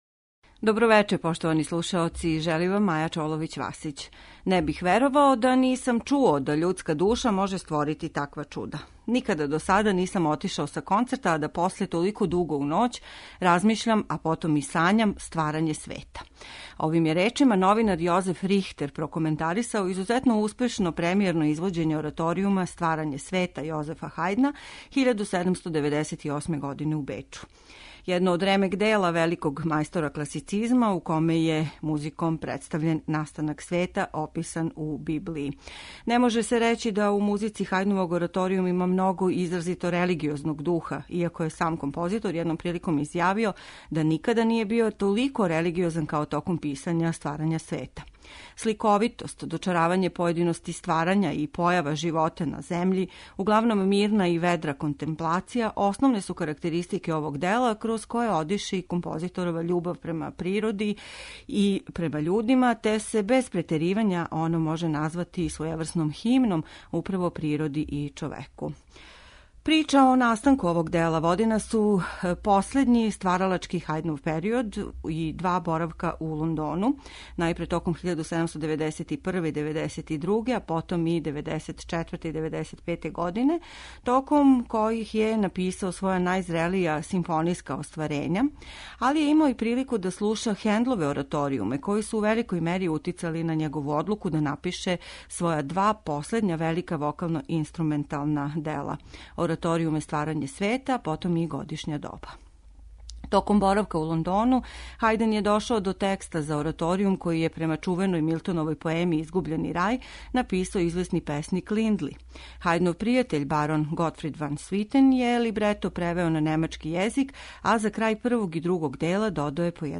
У вечерашњој емисији ћете слушати хорске нумере у извођењу реномираних солиста и Друштва Хендла и Хајдна којима диригује Хари Kристоферс.